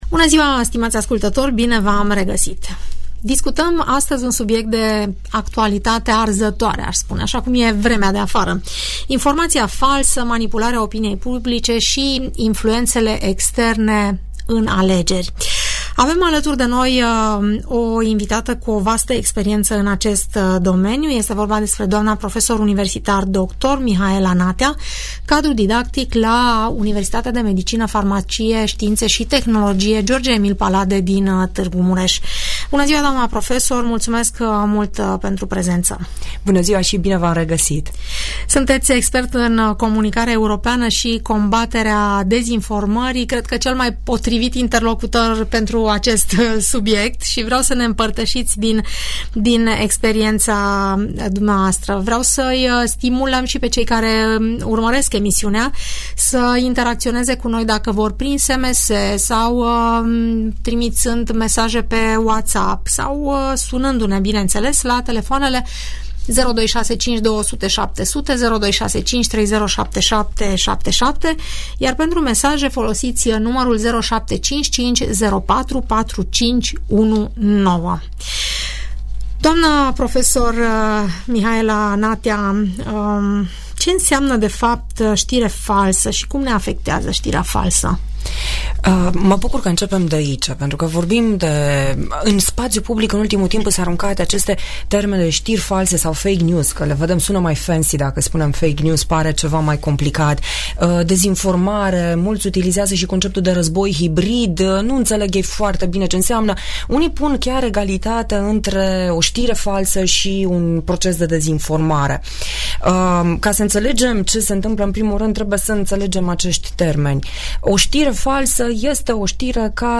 Urmărește dialogul moderat la Radio Tg. Mureș